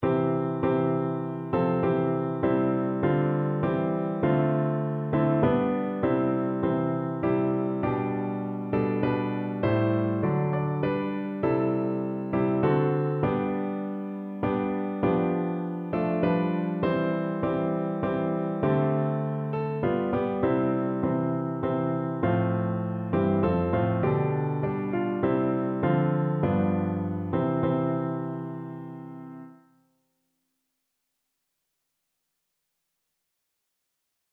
Christian
No parts available for this pieces as it is for solo piano.
3/4 (View more 3/4 Music)
Piano  (View more Intermediate Piano Music)